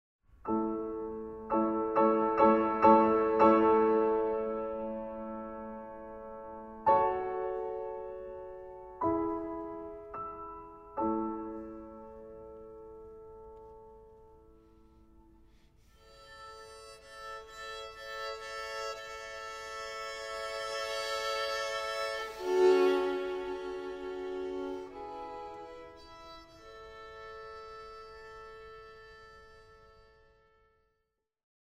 Piano Trio in E minor